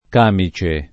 k#mi©e] s. m. — es. con acc. scr.: con un càmice di maglia [kon uj k#mi©e di m#l’l’a] (D’Annunzio); la rustica morbidezza dei càmici di lana [la r2Stika morbid%ZZa dei k#mi©i di l#na] (E. Cecchi) — cfr. camicia